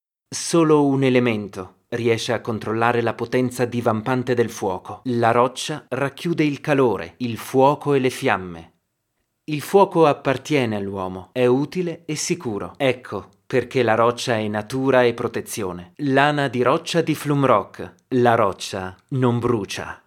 Sprecher Italienischer Muttersprache in der Schweiz.
Sprechprobe: Sonstiges (Muttersprache):